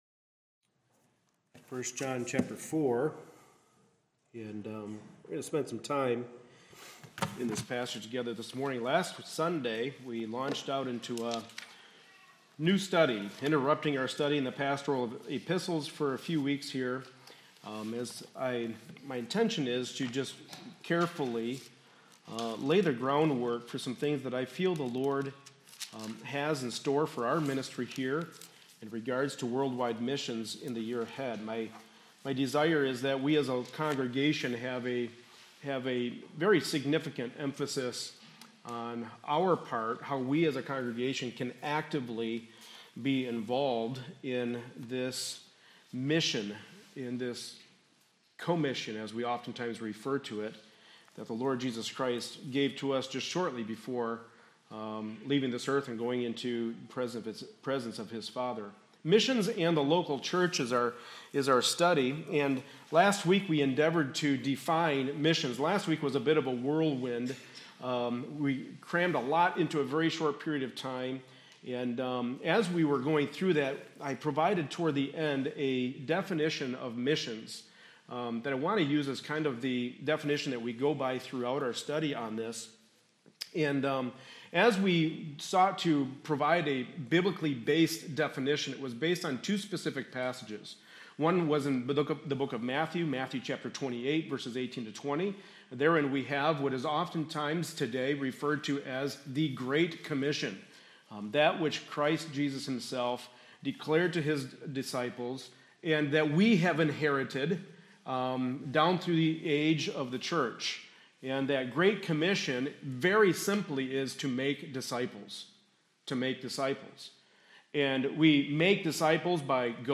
1 John 4:7-5:5 Service Type: Sunday Morning Service Related Topics